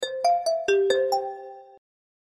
avchat_ring.mp3